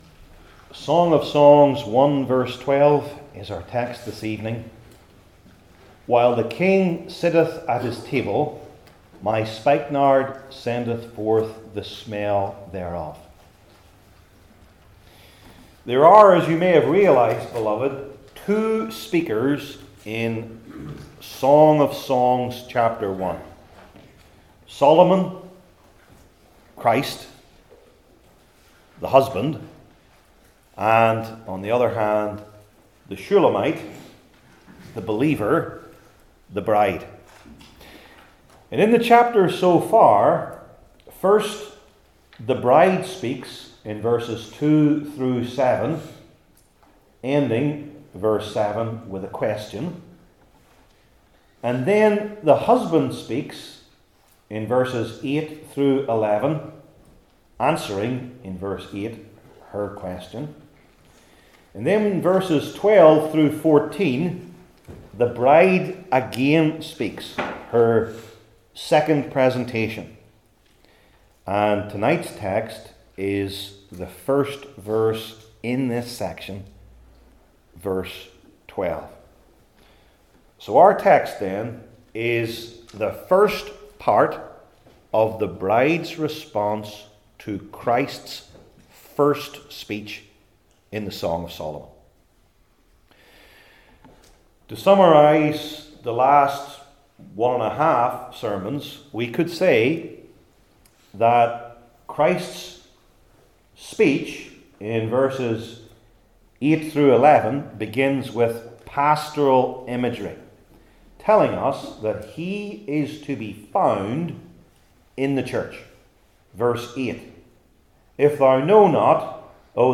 Old Testament Sermon Series I. The Royal Location II.